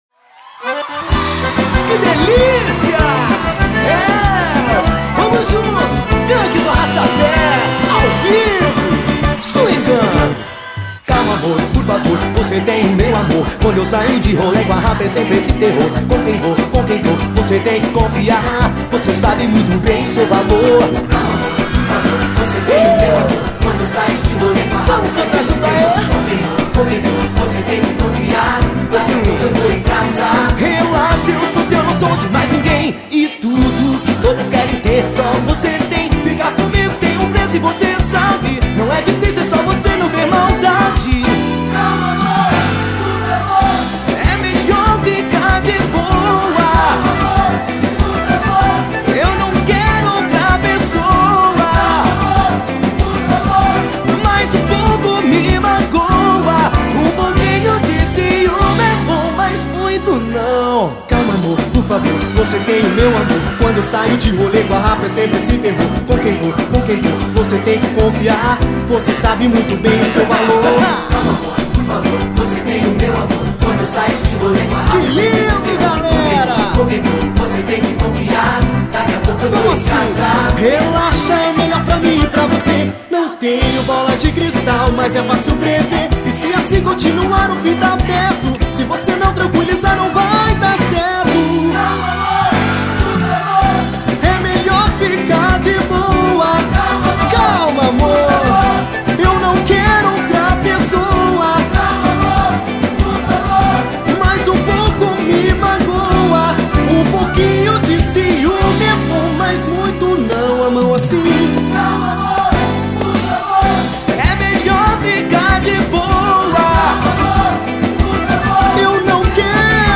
Batidão Gaúcho.